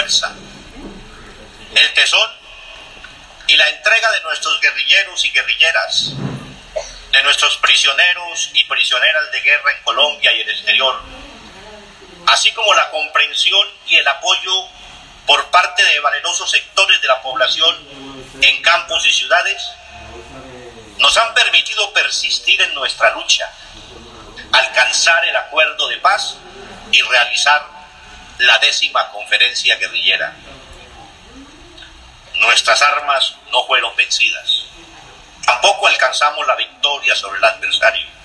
El máximo jefe de las FARC EP, Timoleón Jiménez, alias Timochenko, dijo en su discurso de cierre de la X Conferencia Nacional Guerrillera que en el Proceso de Paz que se firmará este 26 de septiembre en Cartagena, con posterior ratificación por parte del pueblo colombiano, el próximo 2 de octubre, no ha habido vencedores ni vencidos.
«Nuestras armas no fueron vencidas, tampoco alcanzamos la victoria sobre el adversario señaló Timochenko ante mas de 3 mil personas.
Llanuras del Yarí